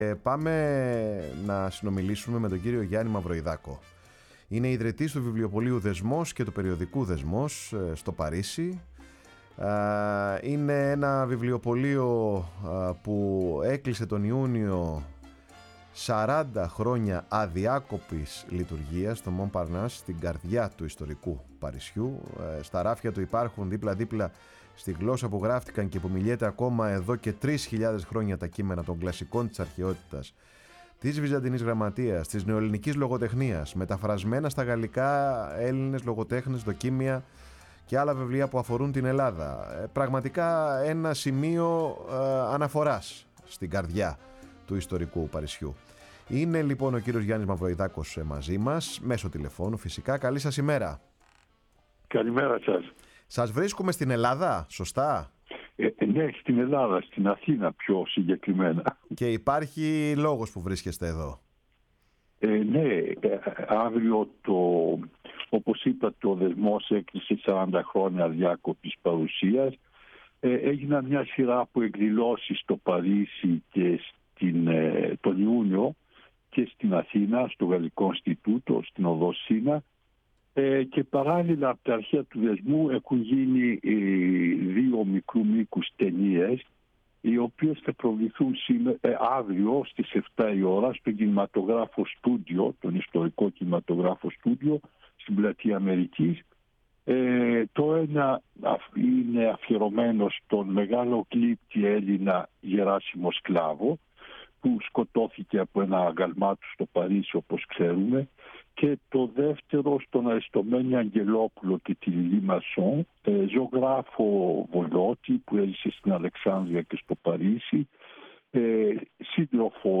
ήταν καλεσμένος τηλεφωνικά